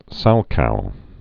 (sălkou)